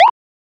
edm-perc-27.wav